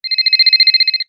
landline.mp3